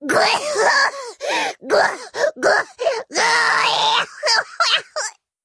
Play, download and share Vyrn Choke original sound button!!!!
vyrn-choke.mp3